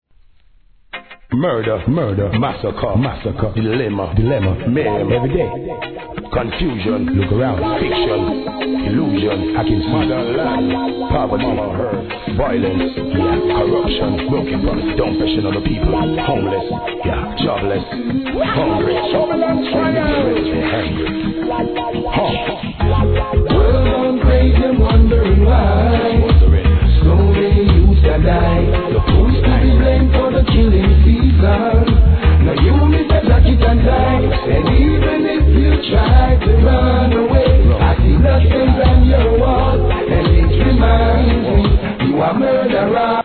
REGGAE
哀愁系ナイスミディアム♪